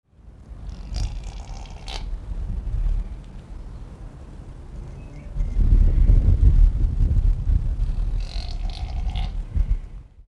Rupicapra_rupicapra_S0341_02_ - NAJUversum - die neue Internet-Plattform für Kinder, Eltern und Lehrer.